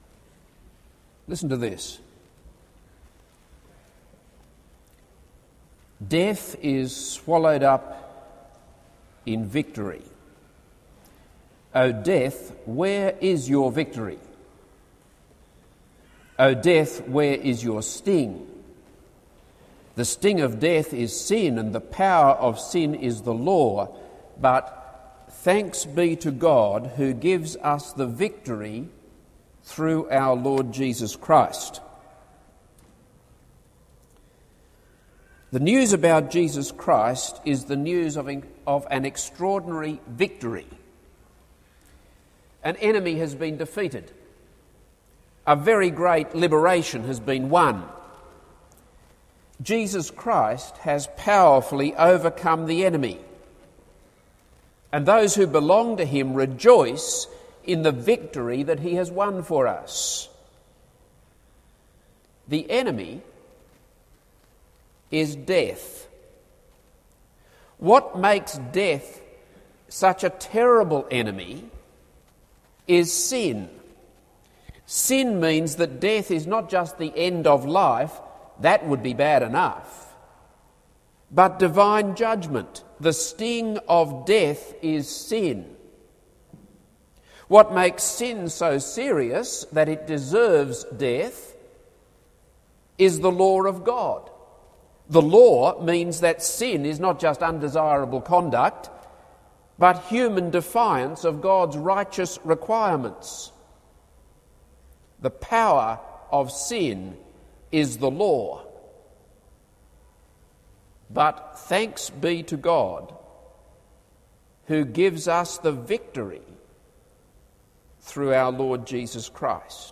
This is a sermon on 1 Samuel 17:41-58.